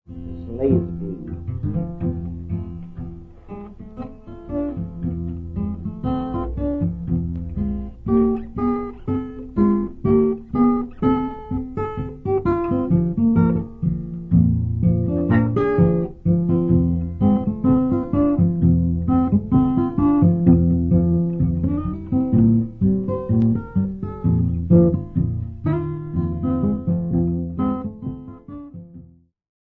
Guitar music (Blues)
Guitar
field recordings
sound recording-musical
Blues guitarist from United States of America